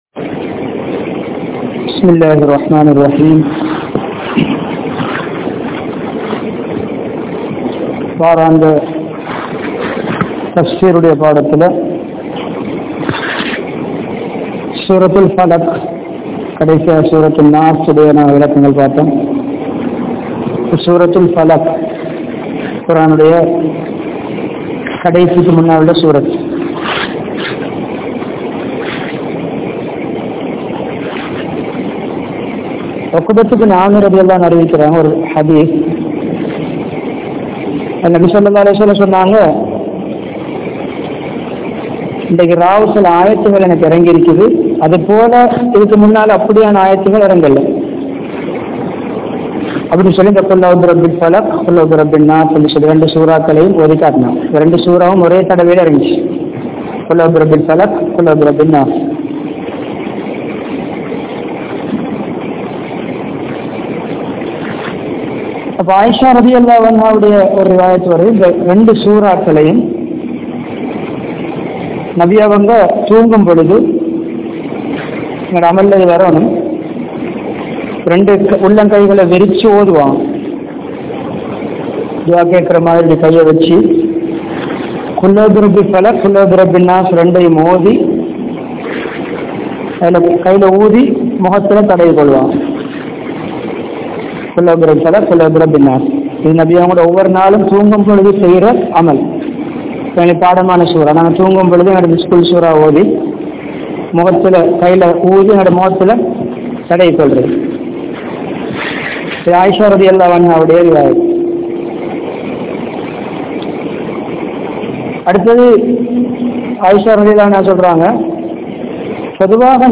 Surah Falaq(Thafseer) | Audio Bayans | All Ceylon Muslim Youth Community | Addalaichenai
Hambantota, Warasamull Jumma Masjidh